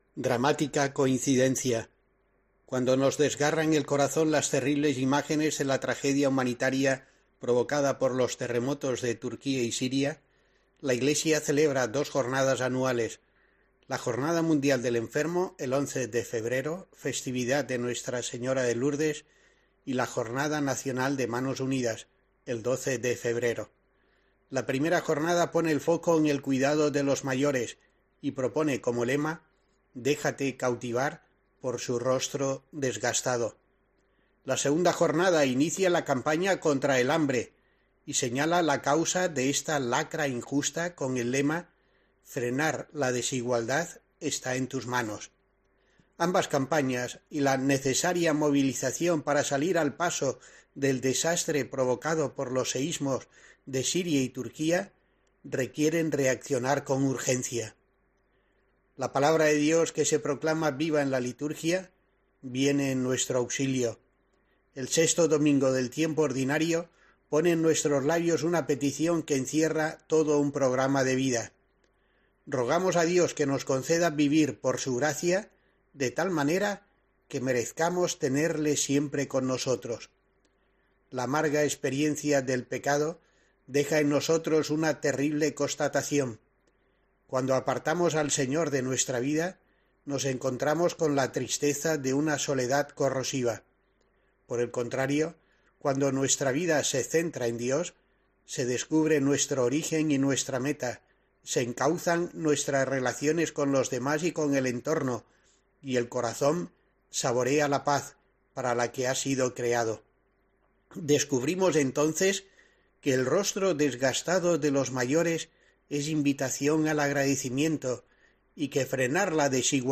La reflexión de monseñor Rico Pavés tiene hoy en cuenta a los damnificados del terremoto así como la Jornada del Enfermo el sábado y la campaña de Manos Unidas el domingo